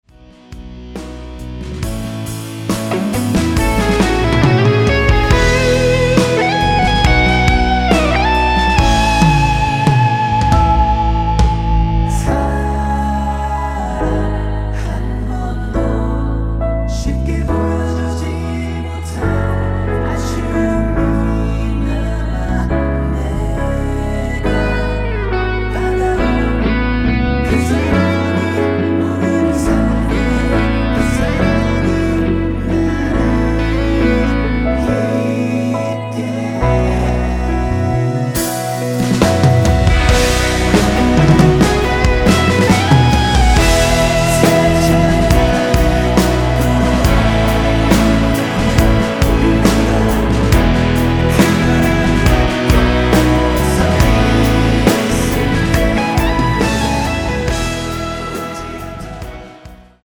원키 코러스 포함된 MR입니다.(미리듣기 확인)
앞부분30초, 뒷부분30초씩 편집해서 올려 드리고 있습니다.